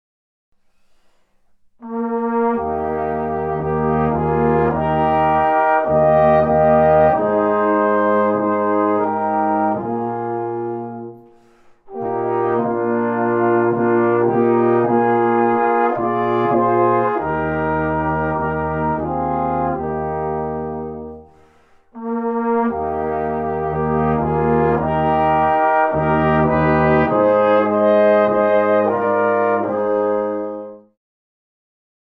Bläsermusik zur Advent- und Weihnachtszeit
für Flügelhorn in B & Tenorhorn in B